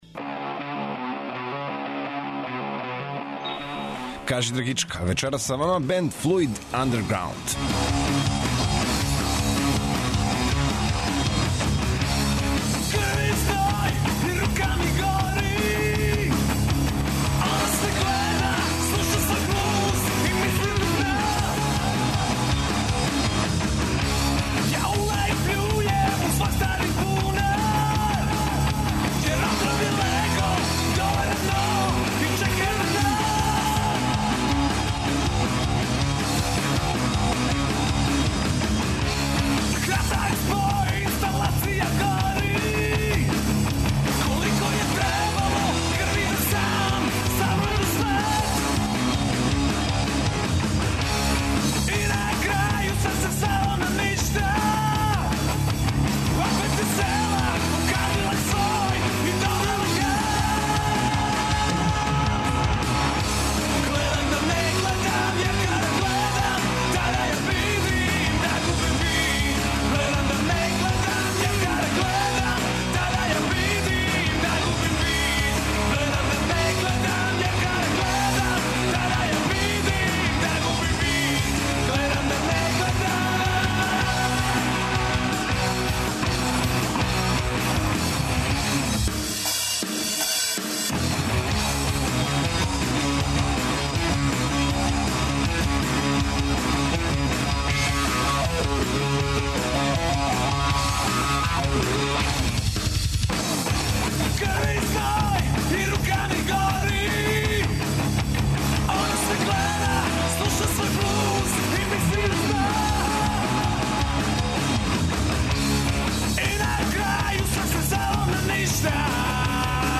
Вечерас ће Вам се представити хард-рок група из Власотинца, ''Fluid Underground''.